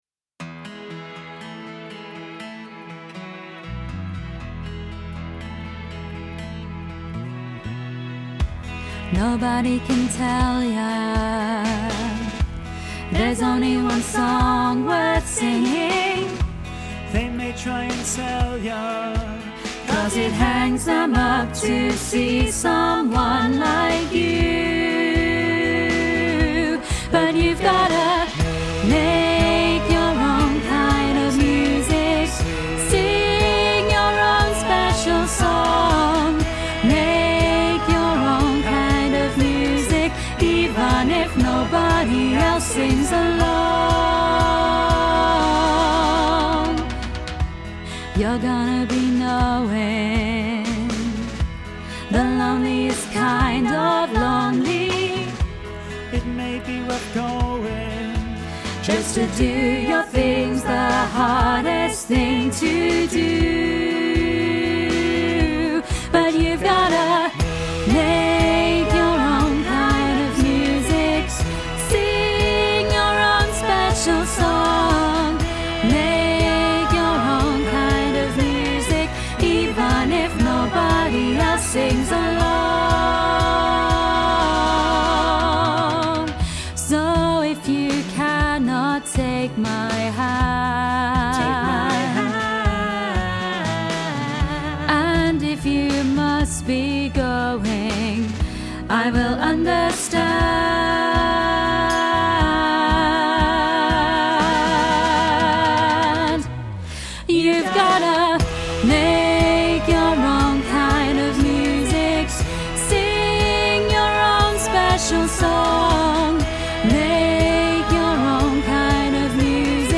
Training Tracks